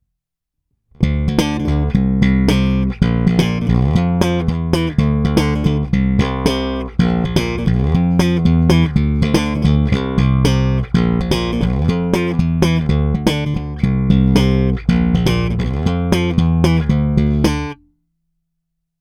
Ukázky jsou nahrány rovnou do zvukové karty a jen normalizovány.
Slap s nepatrně staženým kobylkovým snímačem